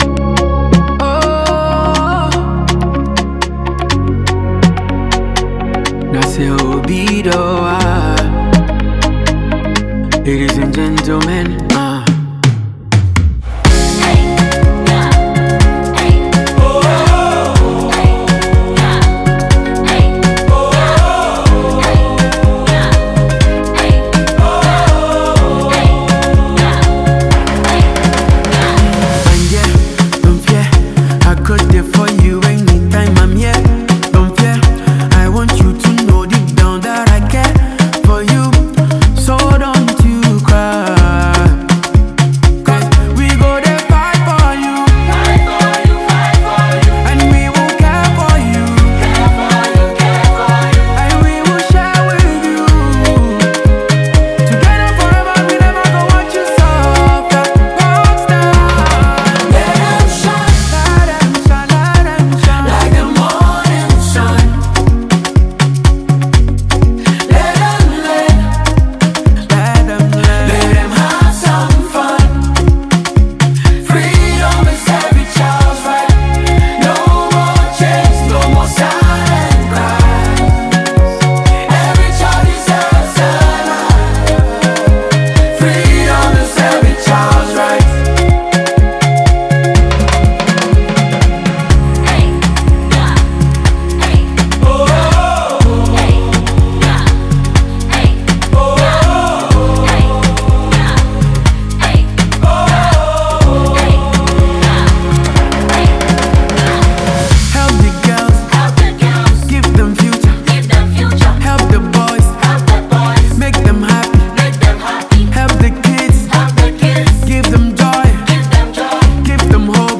Ghanaian Afrobeat and highlife
With his smooth vocals and heartfelt delivery